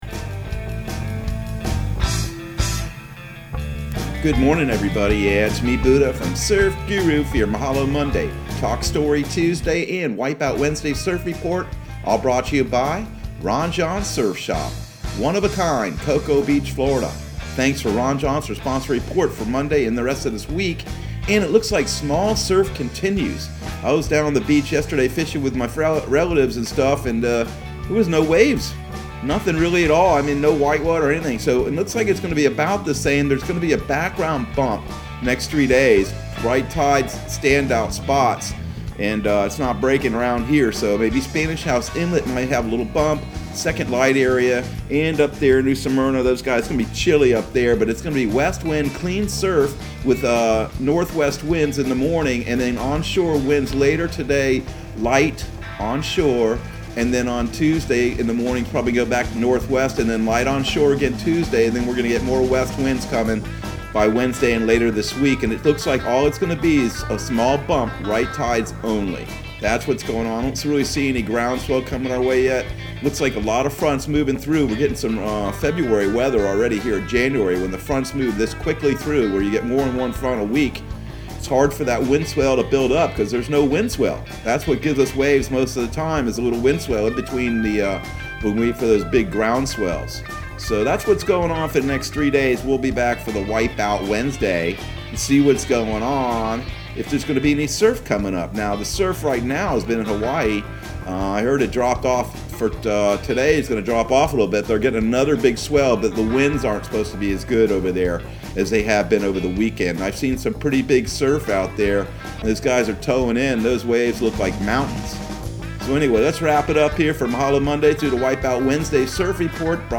Surf Guru Surf Report and Forecast 01/18/2021 Audio surf report and surf forecast on January 18 for Central Florida and the Southeast.